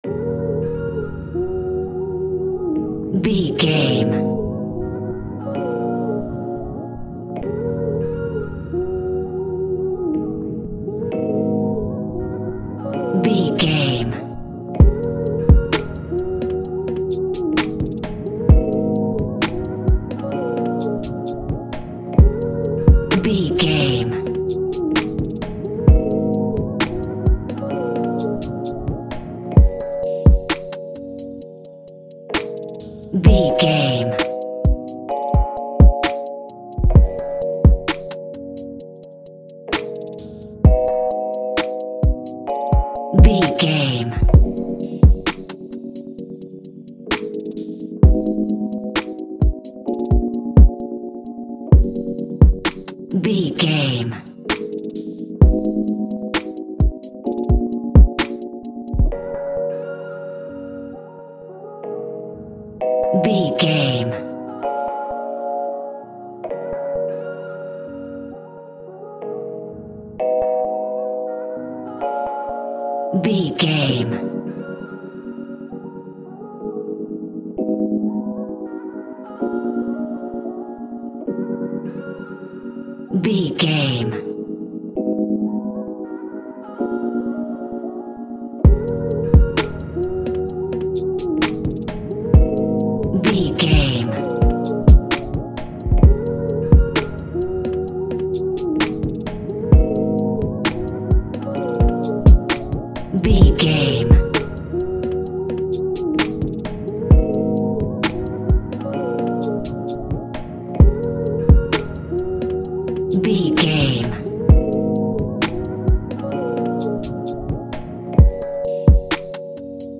Ionian/Major
drums
dreamy
relaxed
smooth
mellow